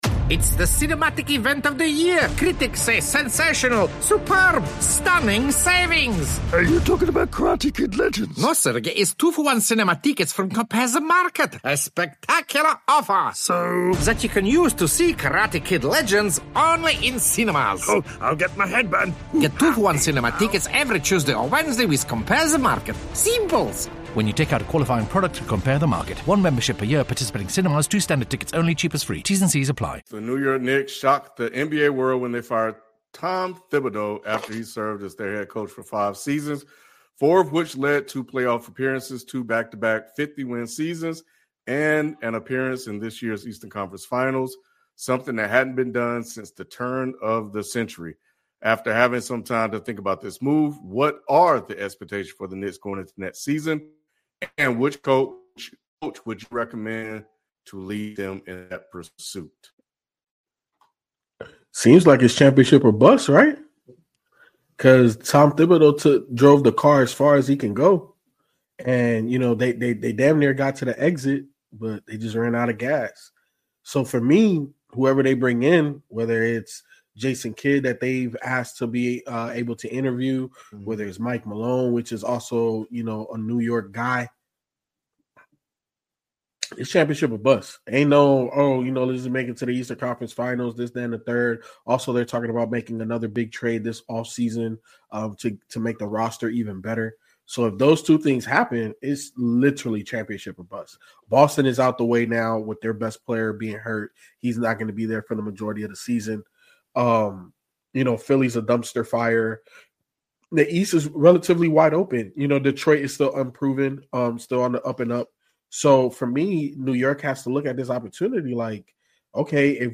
The Dead End Sports crew breaks it all down — the decision, the implications, and the names that should be on New York’s short list.